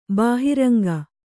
♪ bāhiranga